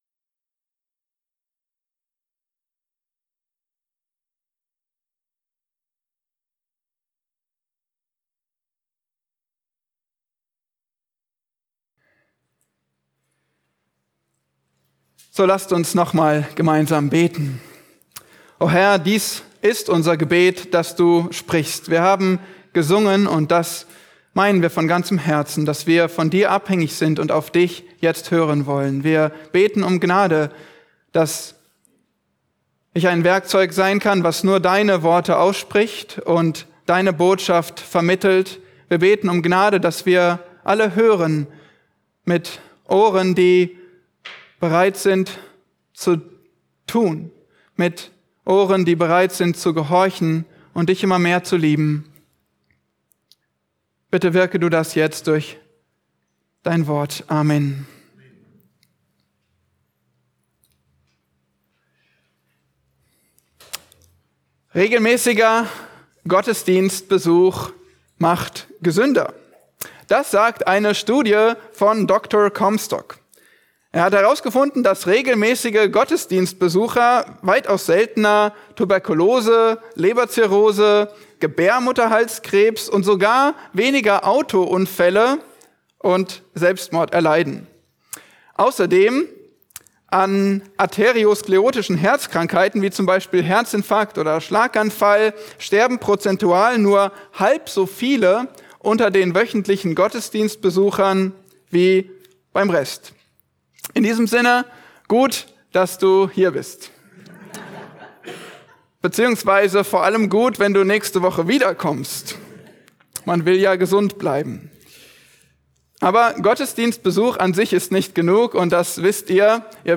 Gastprediger